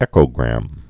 (ĕkō-grăm)